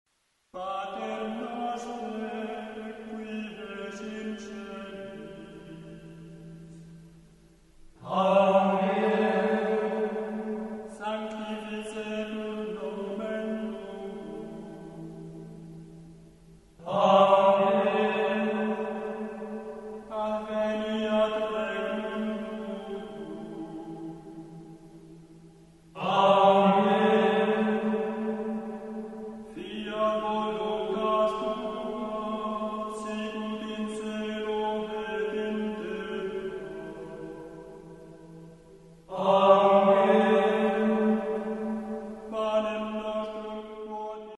Gregoriaans
4 Melismen  Al gauw werden er meer versieringen aangebracht.